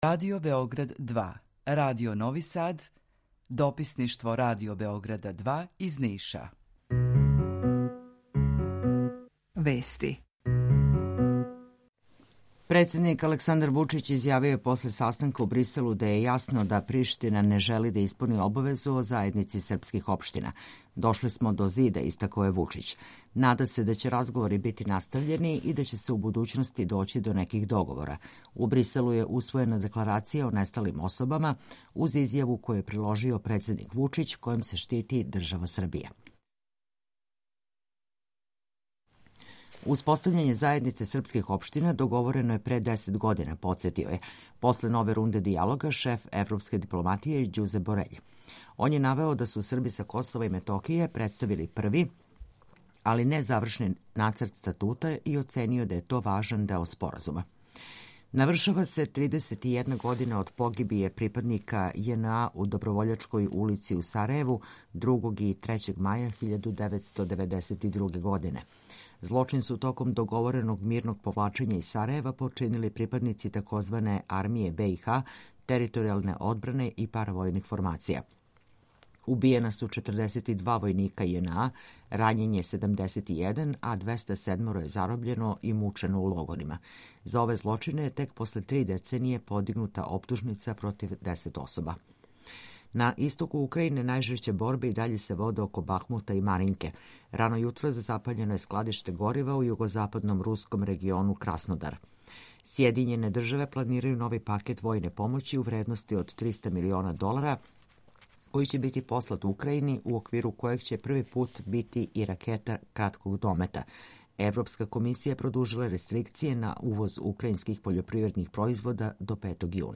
Емисију реализујемо уживо из четири града, четири студија – Радио Републике Српске у Бањалуци, Радио Нови Сад, Радио Београд 2 и дописништво Радио Београда 2 у Нишу.
У два сата, ту је и добра музика, другачија у односу на остале радио-станице.